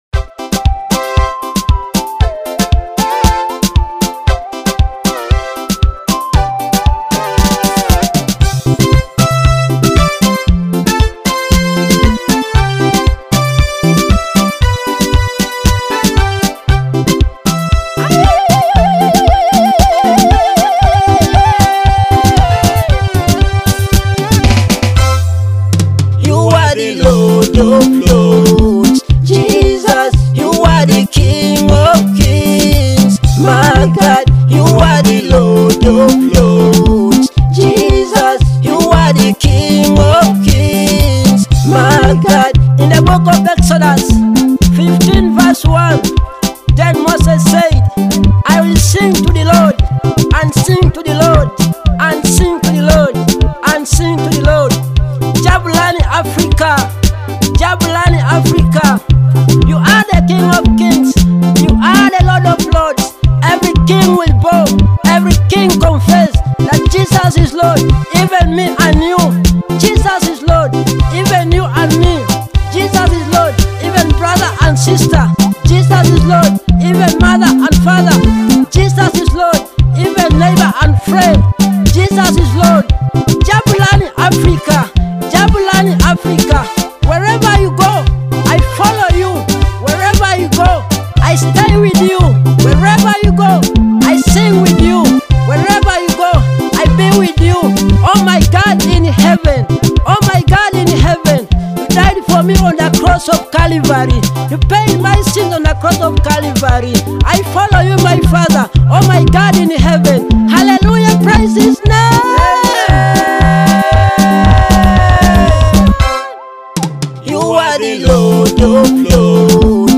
soulful and uplifting song